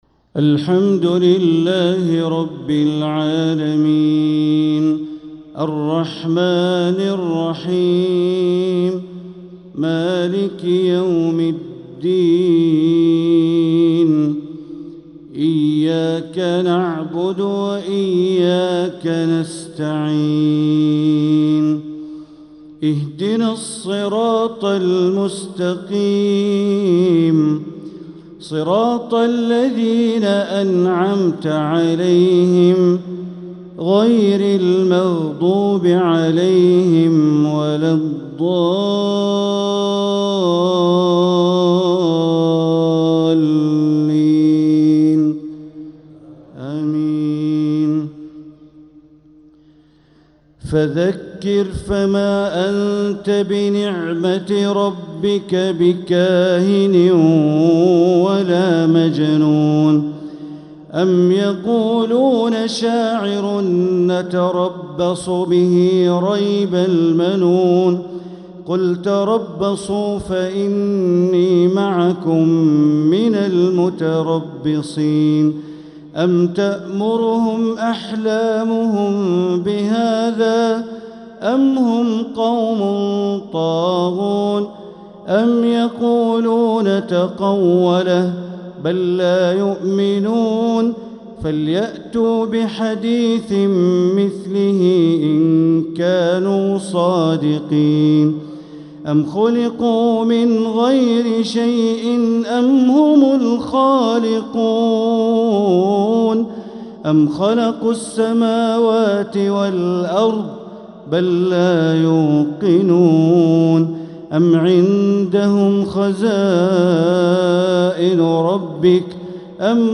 Maghrib prayer from Surat at-Tur 3-2-2025 > 1446 > Prayers - Bandar Baleela Recitations